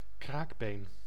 Ääntäminen
IPA : /ˈkɑːtəlɪd͡ʒ/
IPA : /ˈkɑɹtəlɪd͡ʒ/